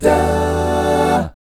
1-EMI7  AA-R.wav